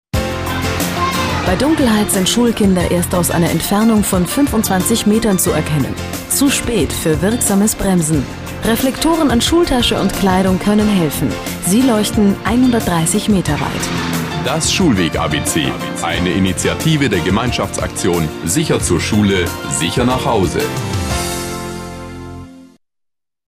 Rundfunkspots - Gemeinschaftsaktion Sicher zur Schule - Sicher nach Hause
Auch in diesem Jahr begleitete die Gemeinschaftsaktion mit den beliebten Schulweg-ABC-Spots im Bayerischen Rundfunk (Bayern 1, Bayern 3 und B5 aktuell) den Schulanfang 2014/15.